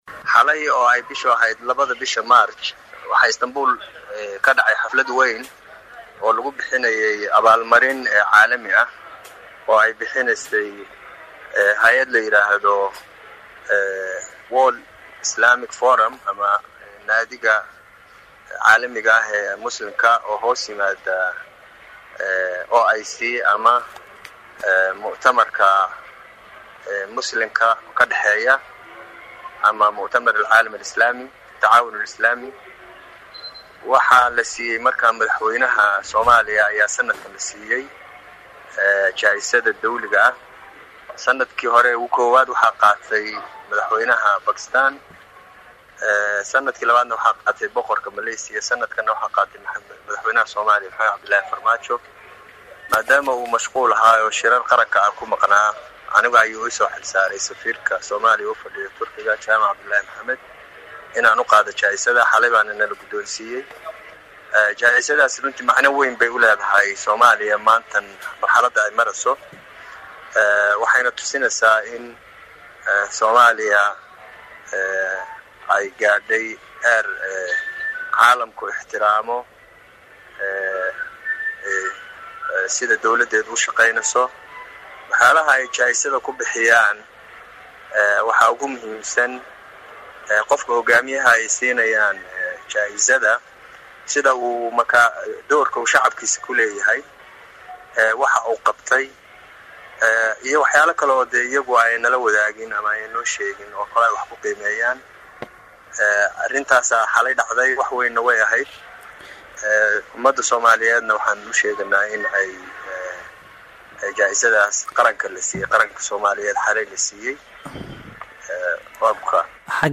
Halkan ka dhageyso Safiirka Soomaaliya ee Turkiga
Safiirka-Soomaaliya-ee-Turkgia.mp3